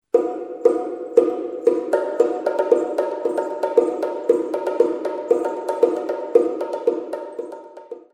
Both CDs contain a variety of all Percussion Music